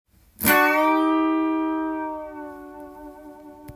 ②のチョーキングはダブルチョーキングと言い
その名の通りの２つ一緒にチョーキングします。